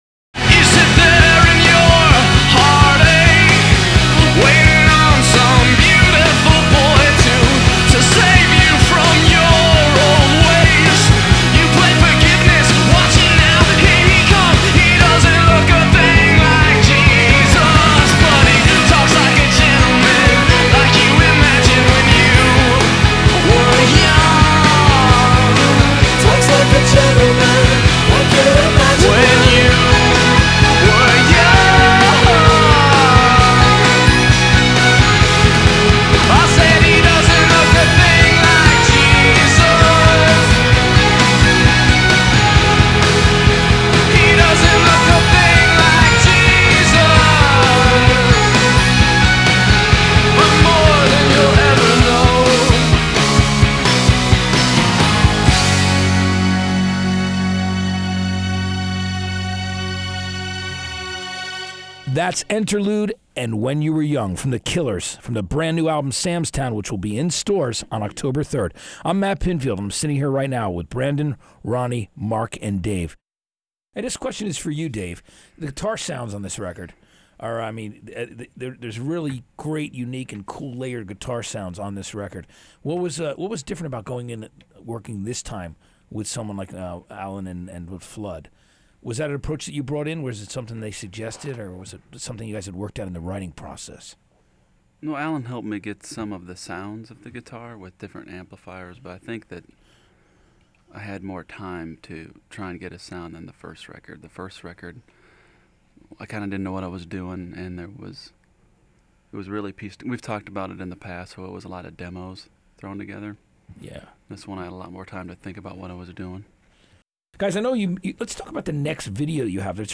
01 The Killers Matt Pinfield Interview (Exclusive To Tower Records).flac